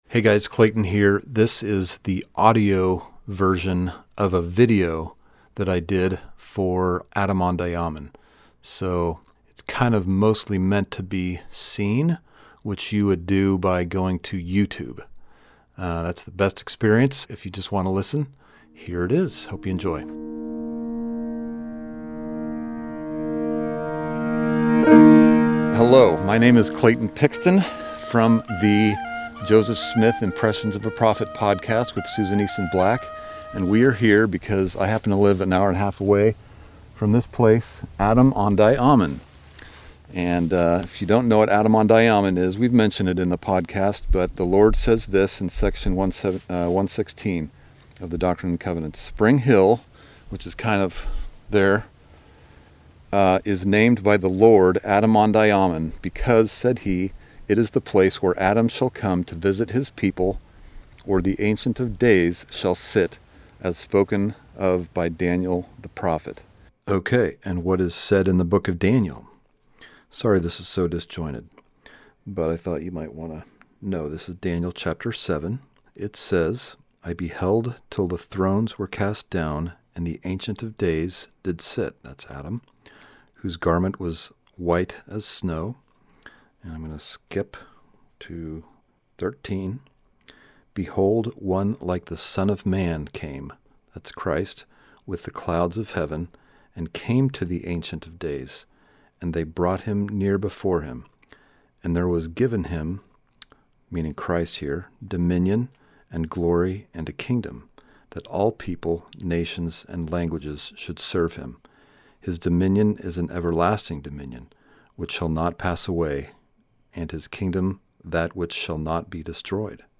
On Location: Adam-ondi-Ahman